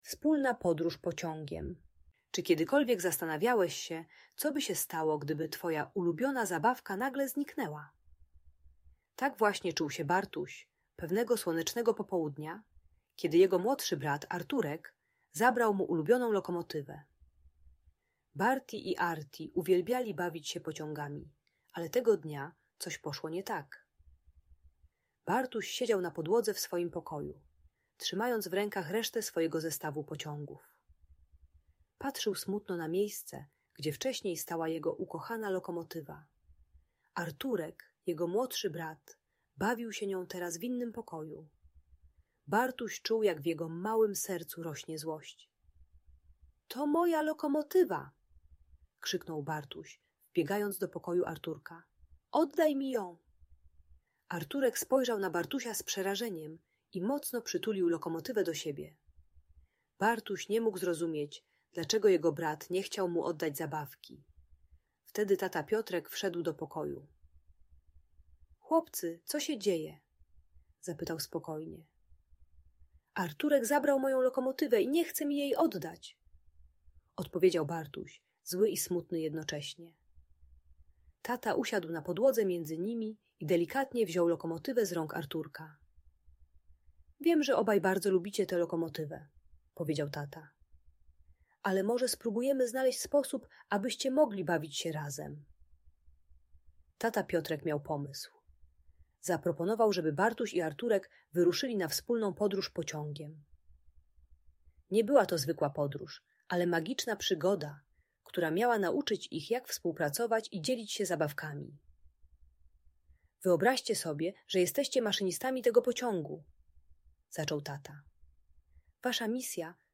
Opowieść o wspólnej podróży pociągiem - Audiobajka